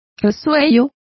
Complete with pronunciation of the translation of snuffle.